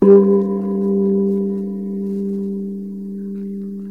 Sound the Gong
gong.wav